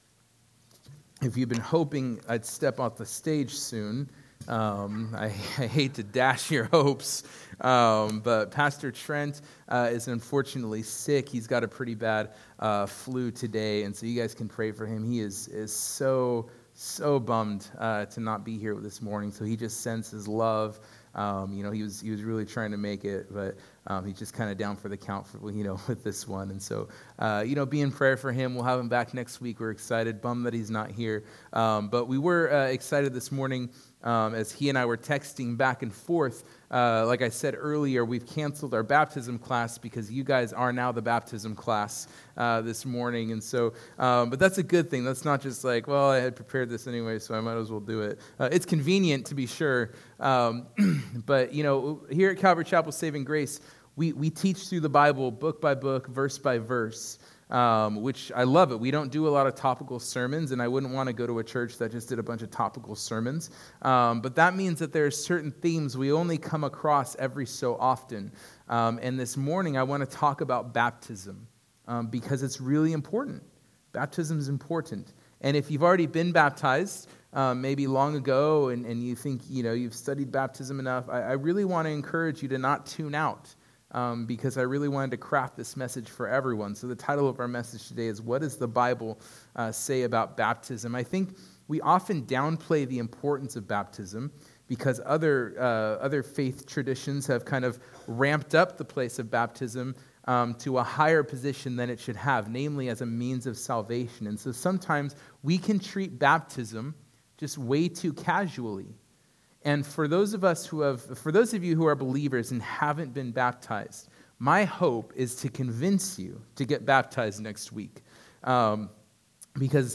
What+Does+the+Bible+Say+About+Baptism+2nd+Service.mp3